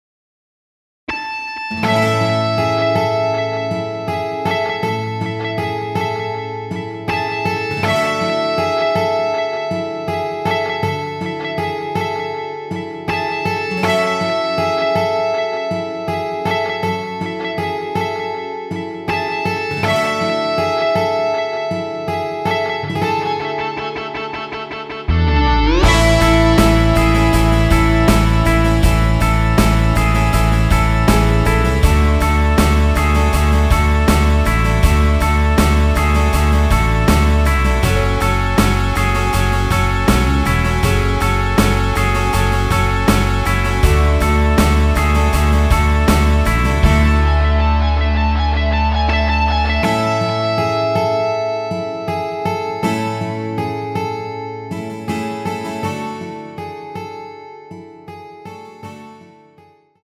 원키에서(+5)올린 MR입니다.
앞부분30초, 뒷부분30초씩 편집해서 올려 드리고 있습니다.